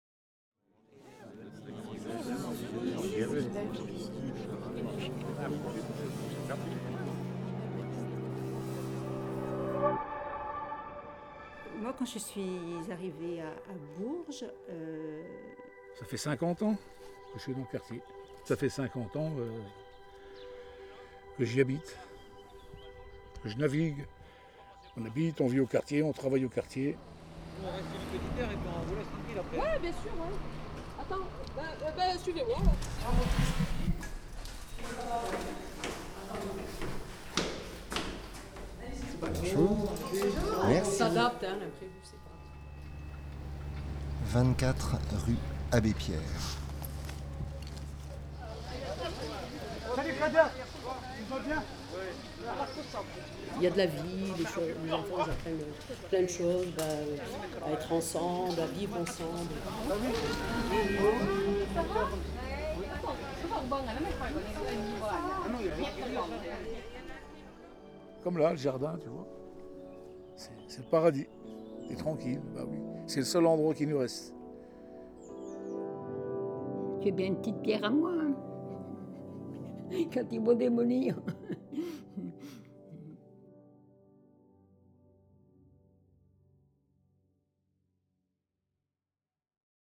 La tour bidule était un ensemble de 111 logements construite en 1954, elle fut détruite en 2011 lors des bombardements des artificiers de l’entreprise DomolTout, voici des témoignages de ses anciens habitants.